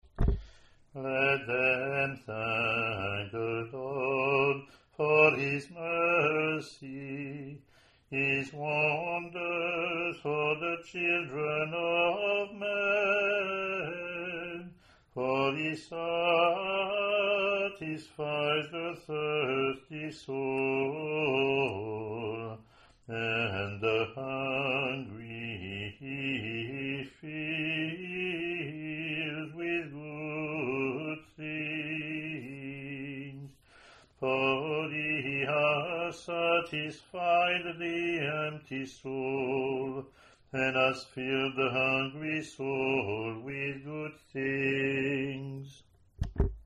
Click to hear Communion (
English antiphon and verse